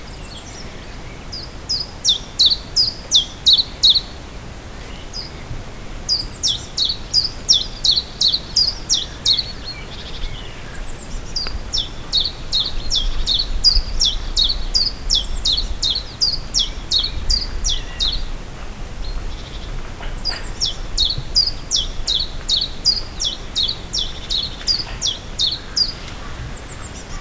Abb. 05: Zilpzalp
zilpzalp-mono22.wav